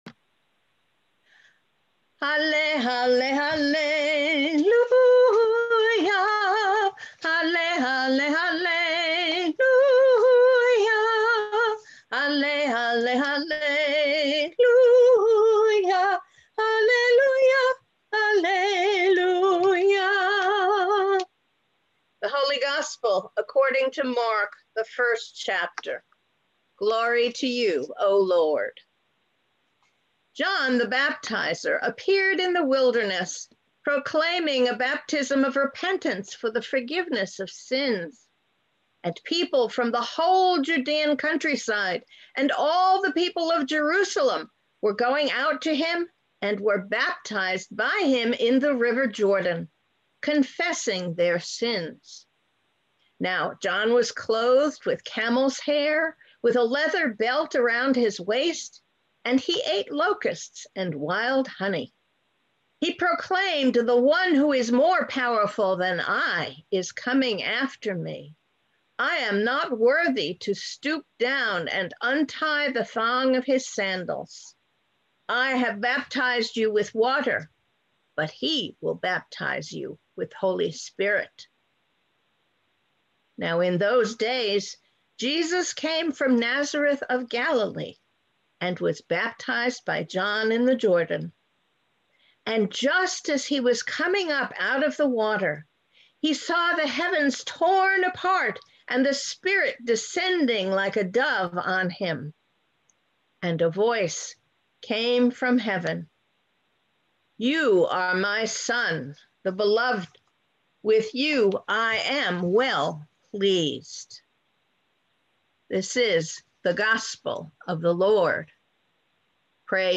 Sermons | Lutheran Church of the Epiphany and Iglesia Luterana de la Epifania